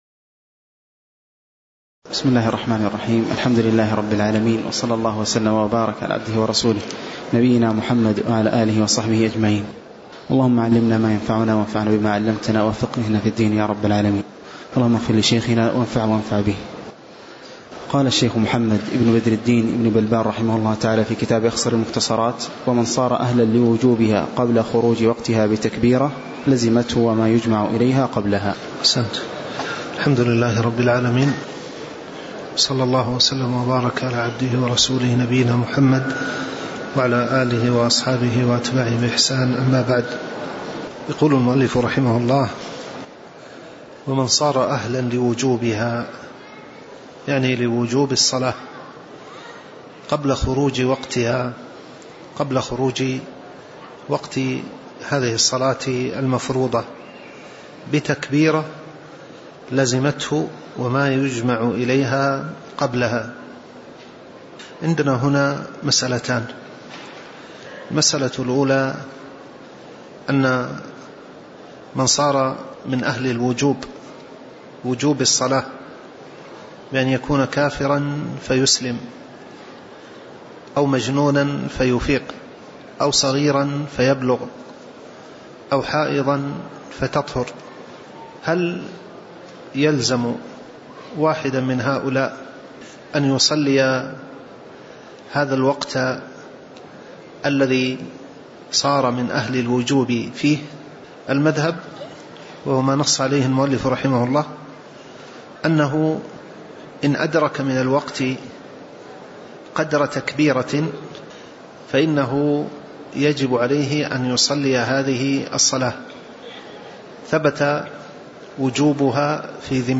تاريخ النشر ٢١ جمادى الأولى ١٤٣٩ هـ المكان: المسجد النبوي الشيخ